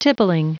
Prononciation du mot tippling en anglais (fichier audio)
Prononciation du mot : tippling